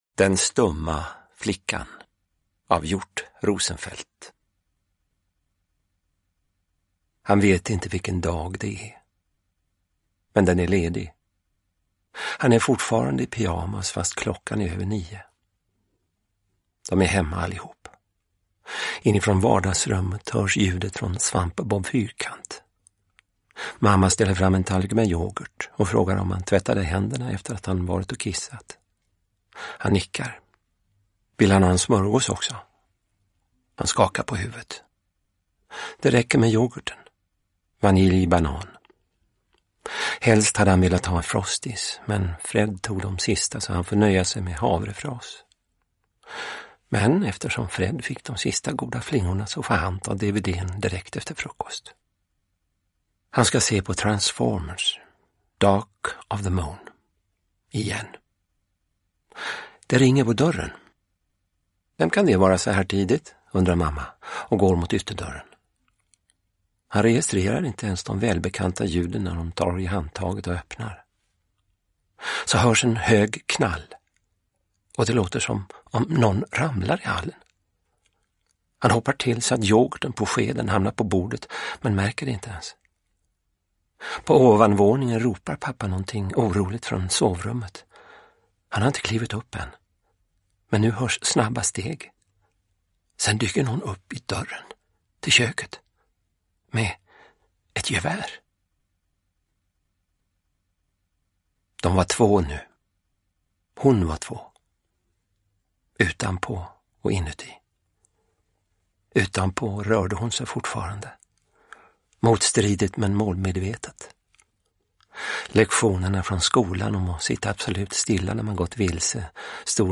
Den stumma flickan – Ljudbok – Laddas ner
Uppläsare: Niklas Falk